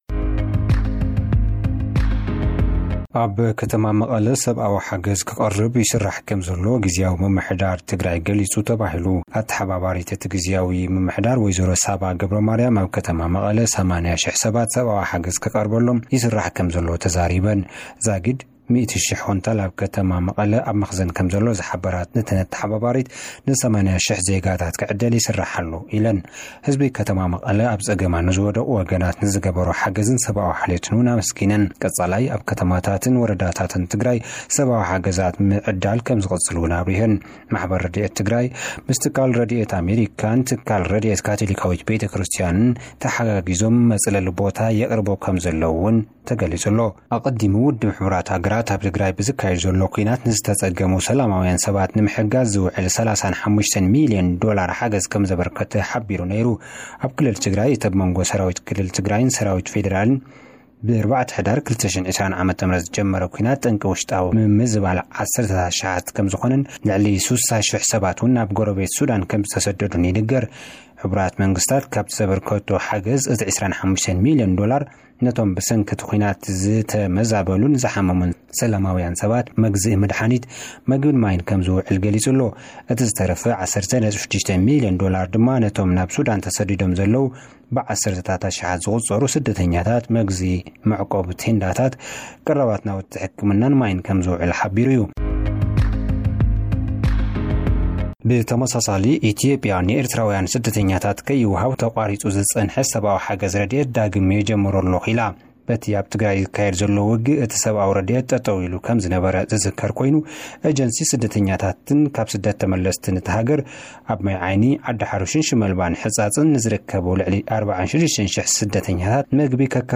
ንህዝቢ መቐለን ንኤርትራዊያን ስደተኛታትን ረዲኤት ክወሃብ'ዩ። (ጸብጻብ)